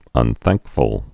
(ŭn-thăngkfəl)